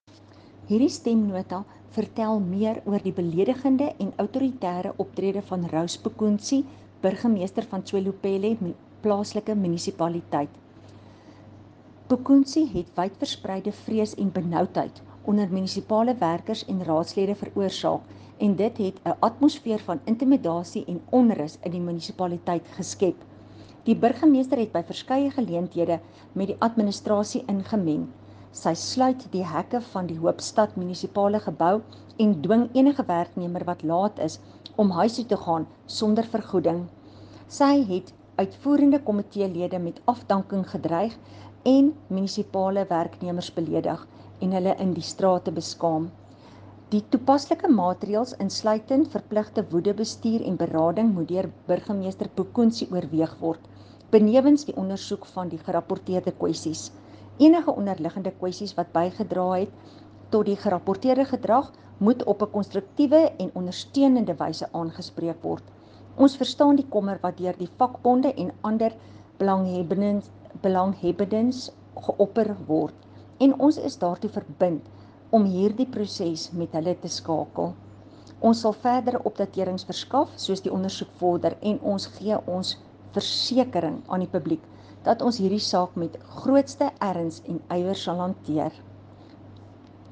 Afrikaans soundbites by Cllr Estelle Pretorius.